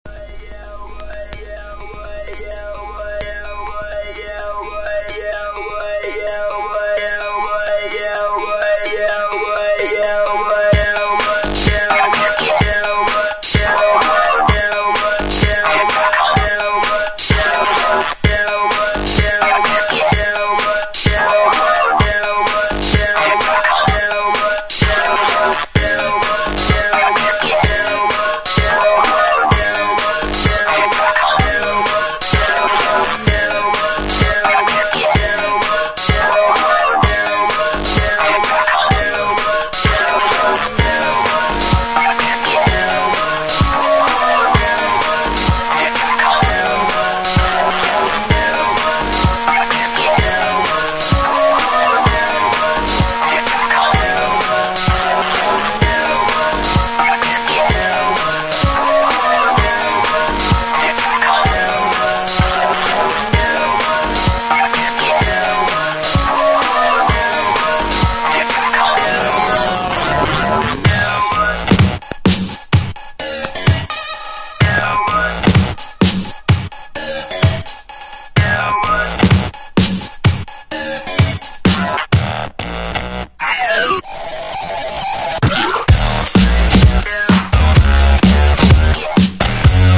UKの4人組新鋭バンド
モジュレイター･ヴォイスに、粘っこいシンセ･ベースが絡む、ファンキーなロッキン･エレクトロ･ディスコ。